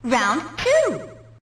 snd_boxing_round2.ogg